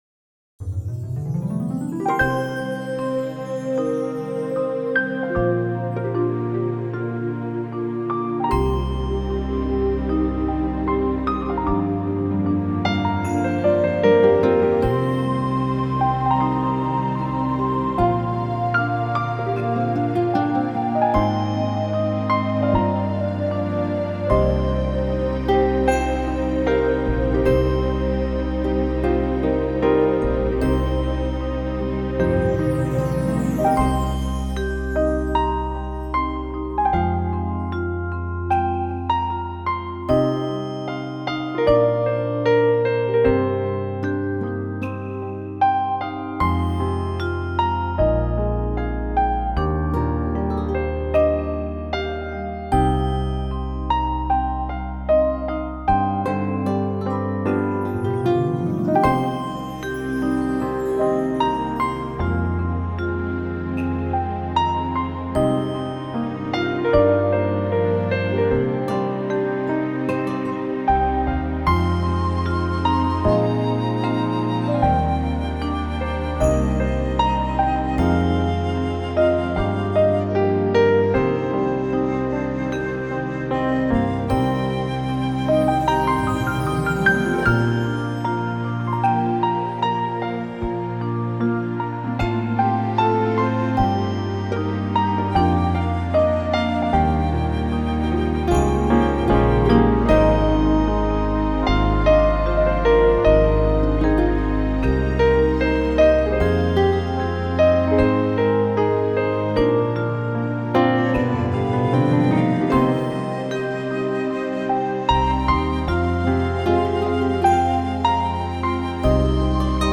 以休闲舒适、浪漫清凉的SPA轻音风格带给你惬意的听觉感受。